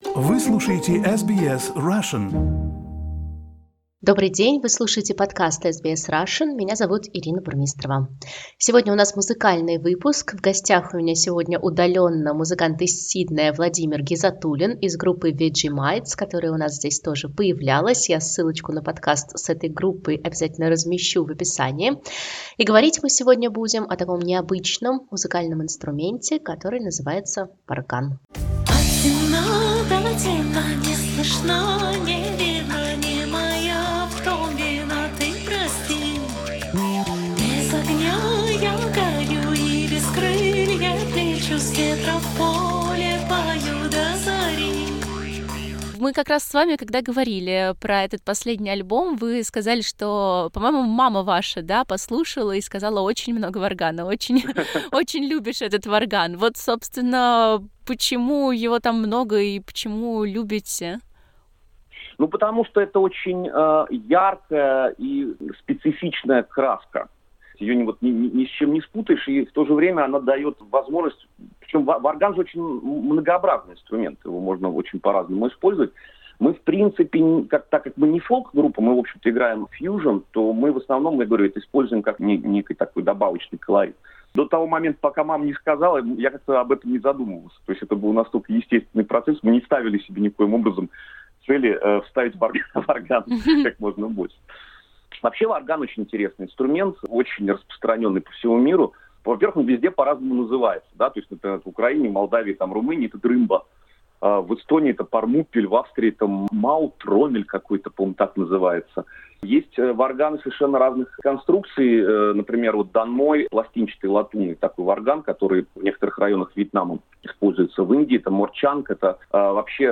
In our podcast, he talks about this instrument and plays various jew's harps.